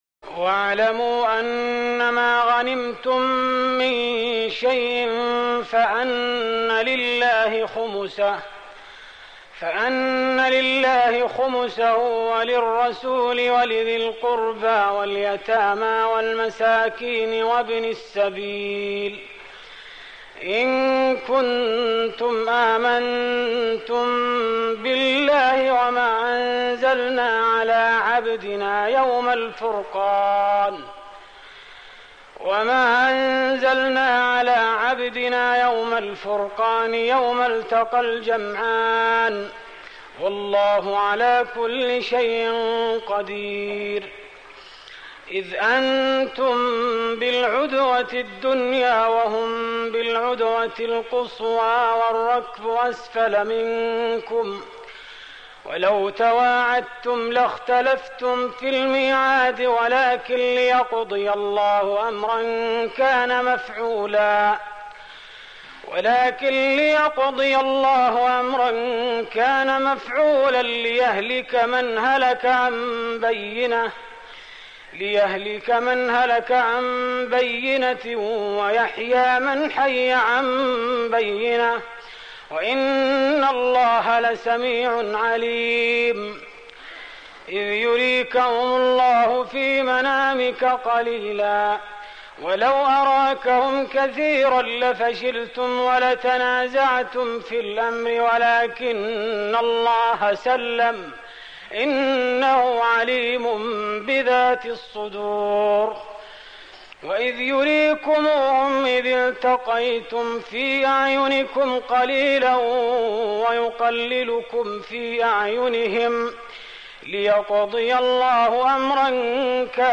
تهجد رمضان 1416هـ من سورة الأنفال (41-75) و التوبة (1-25) Tahajjud Ramadan 1416H from Surah Al-Anfal and At-Tawba > تراويح الحرم النبوي عام 1416 🕌 > التراويح - تلاوات الحرمين